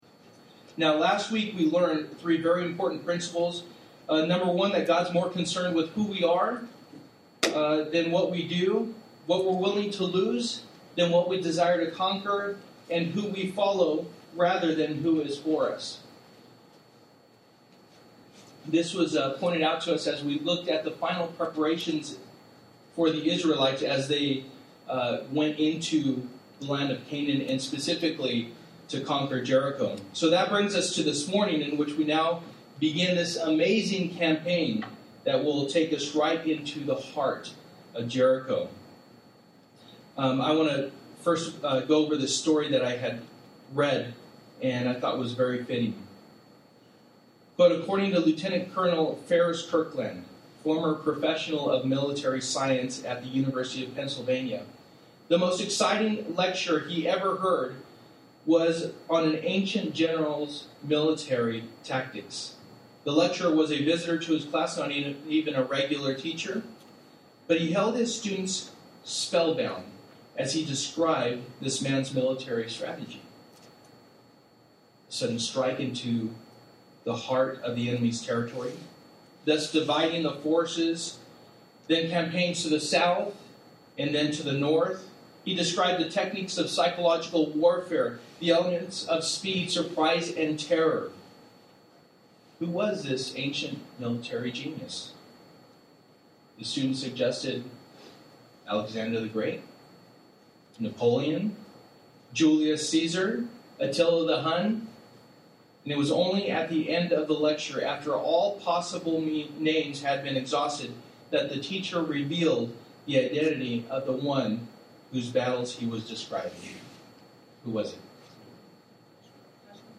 Joshua Passage: Joshua 6:1-27 Service: Sunday Morning %todo_render% « Final Preparations Judgement